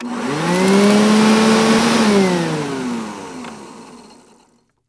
The sound of opening and closing the vacuum cleaner - İndir Materyali İndir Bu materyalin etiketi henüz eklenmemiştir.